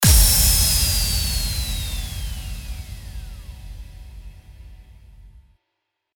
FX-495-IMPACT
FX-495-IMPACT.mp3